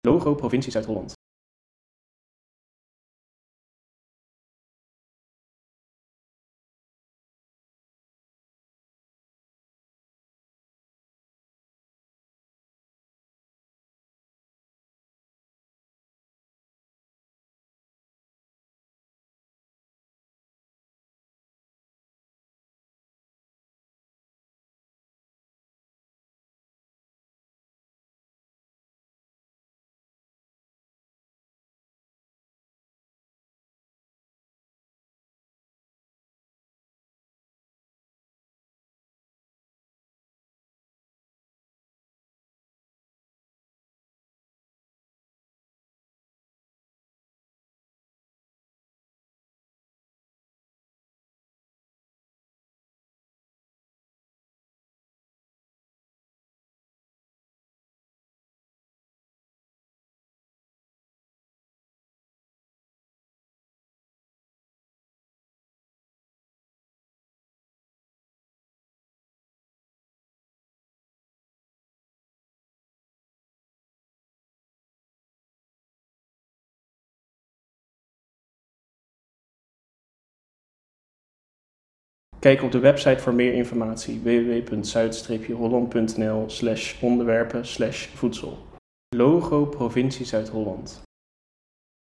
provincie_zuid-holland-audiodescriptie.mp3